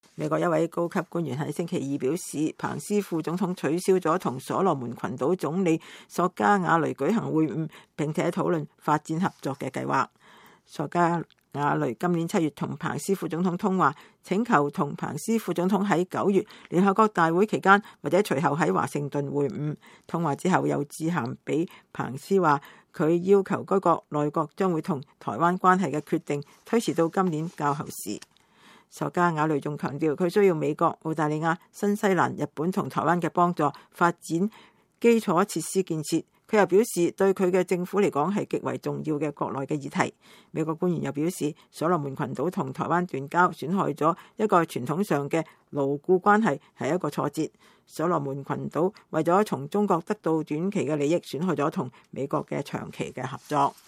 彭斯副總統週二在傳統基金會中講話。